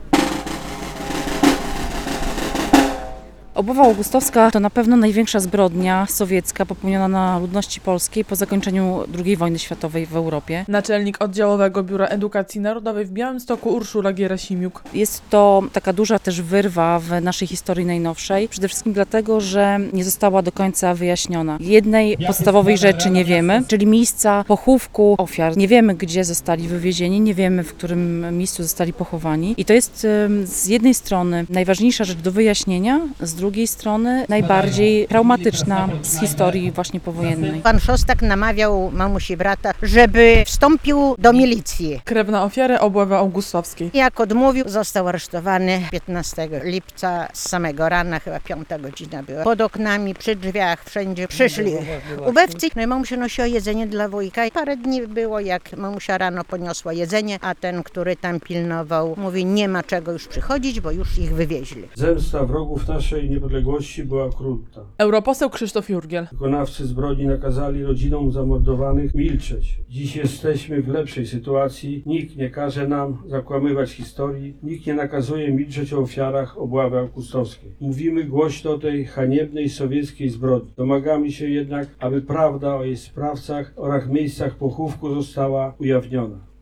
W niedzielę (17.07) przy Wzgórzu Krzyży w Gibach uczczono ofiary Obławy Augustowskiej – największej powojennej zbrodni sowieckiej na Polakach po II wojnie światowej.
relacja